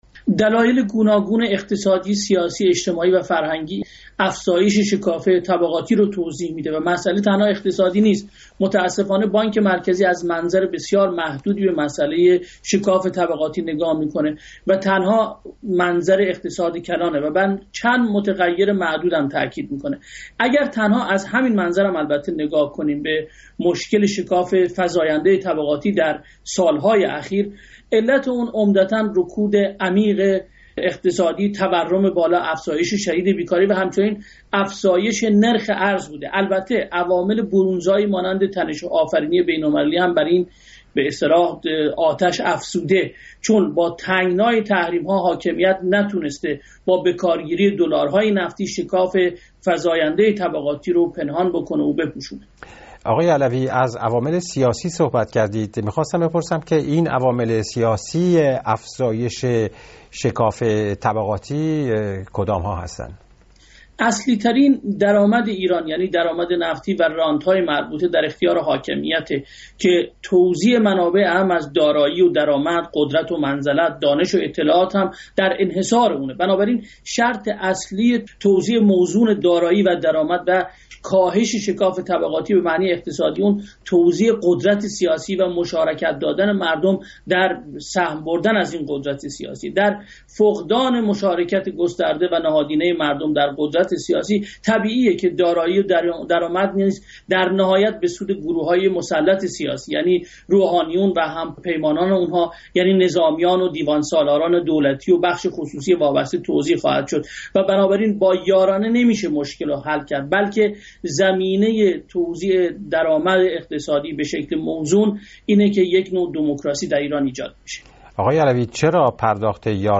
کارشناس اقتصاد مقیم سوئد